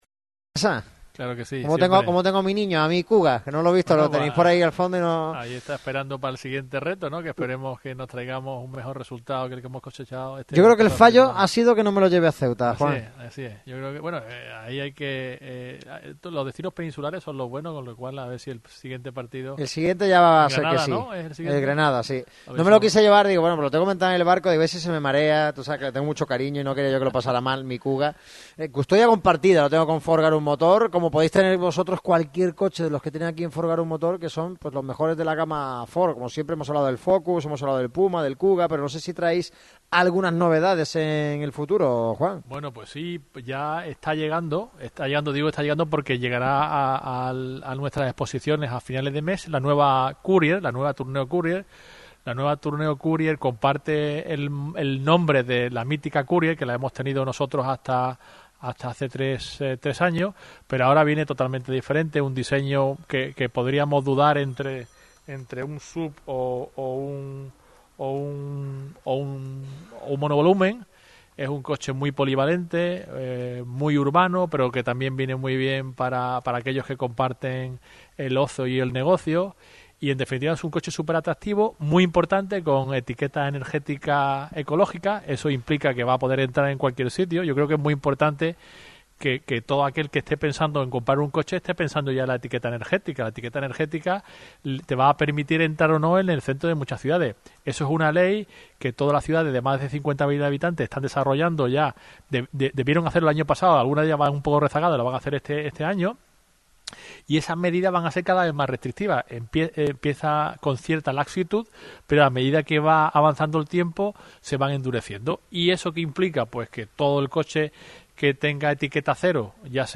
Radio Marca Málaga vuelve una vez más a Ford Garum Motor, en la avenida Luis XXIII, concesionario oficial de la marca estadounidense en la capital costasoleña, de los miembros por excelencia en la familia de la radio del deporte.